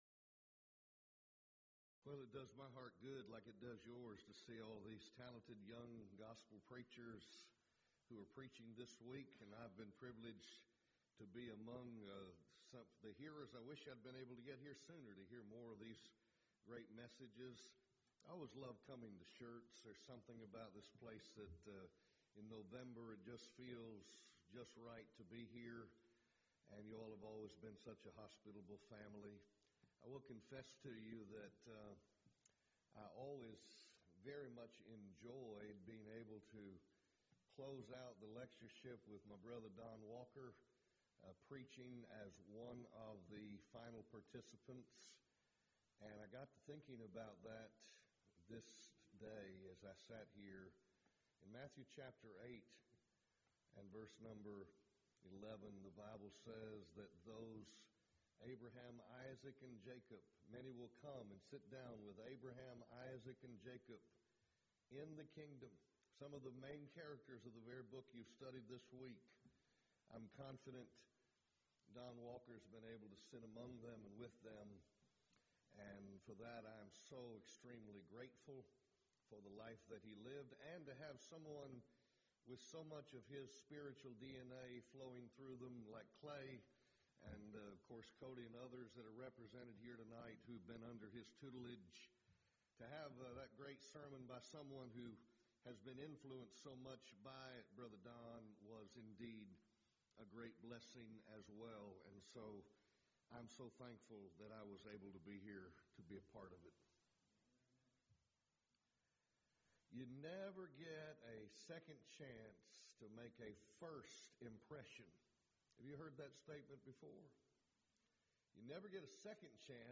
Event: 16th Annual Schertz Lectures
lecture